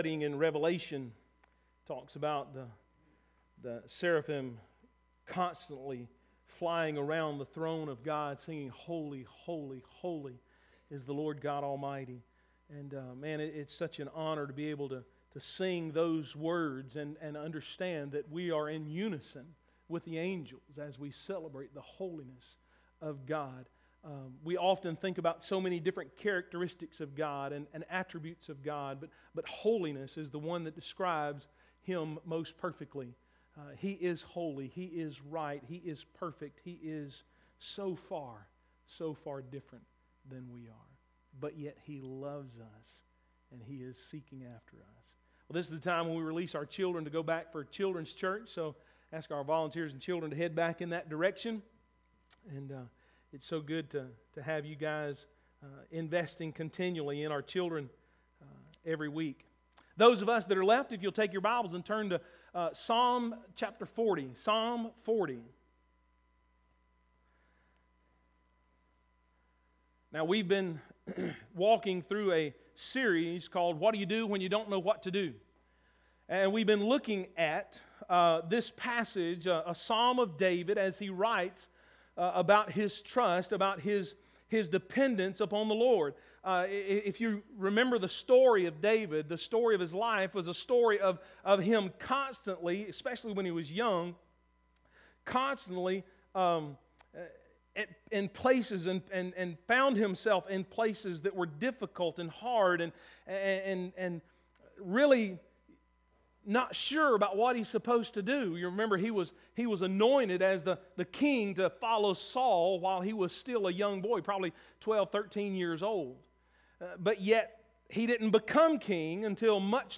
Full sermon was not recorded due to technical difficulties.